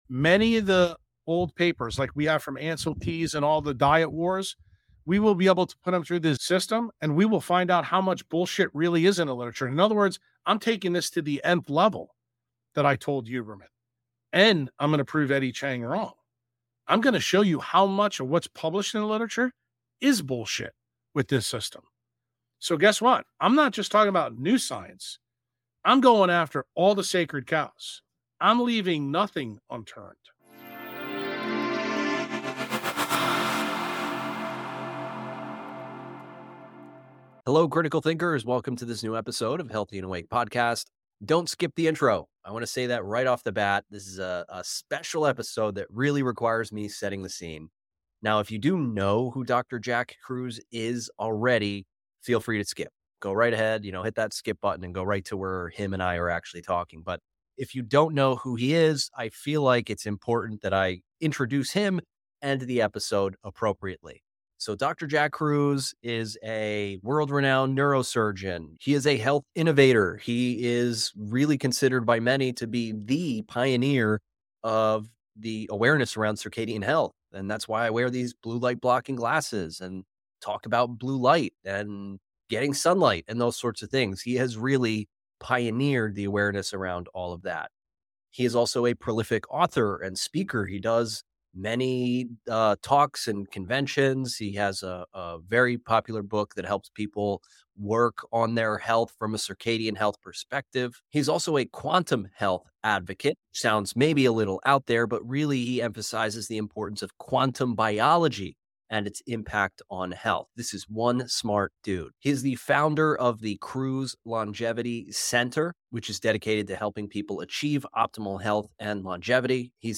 While I typically maintain a clean tone, I encourage my guests to speak freely, so be aware that this episode may not be suitable for sensitive listeners.